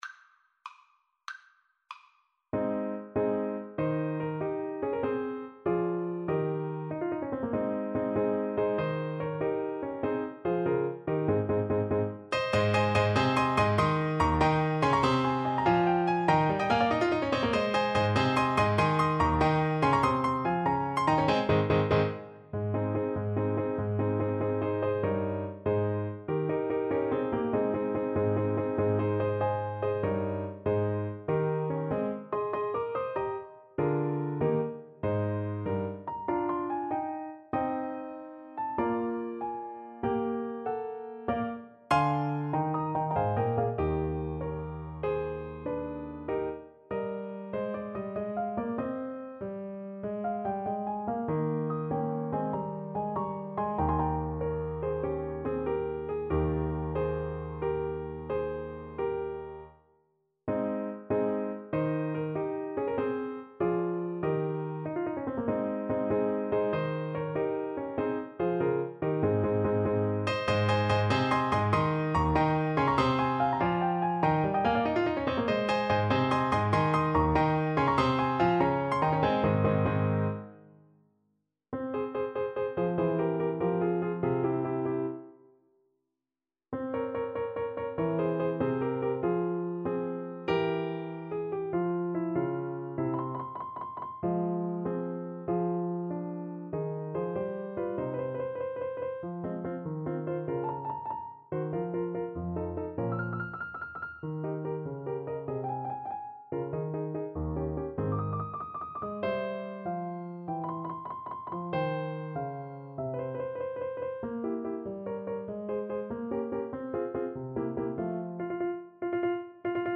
Alto Saxophone
6/8 (View more 6/8 Music)
Rondo: Allegro .=96 (View more music marked Allegro)
Eb4-Ab6
Classical (View more Classical Saxophone Music)